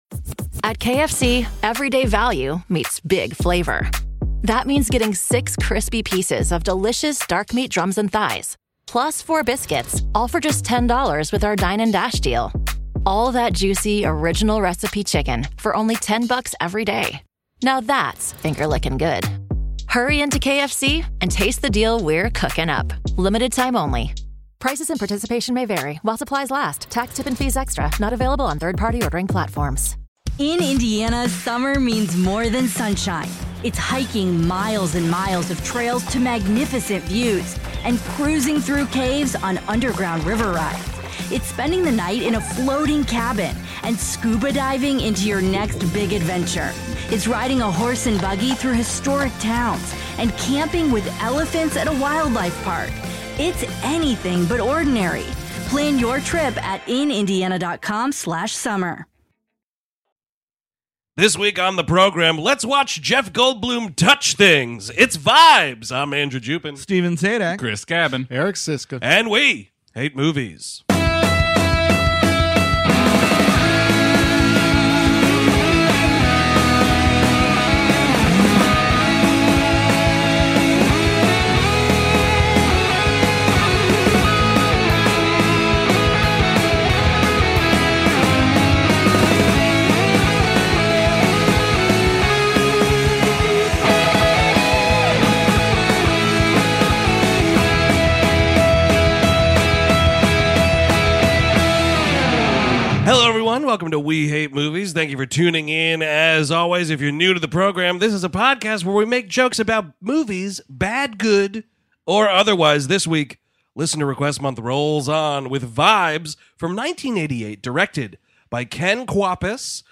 On this week's episode, the 2019 Listener Request Month rolls on as the guys chat about the ridiculous 1988 psychic comedy, Vibes!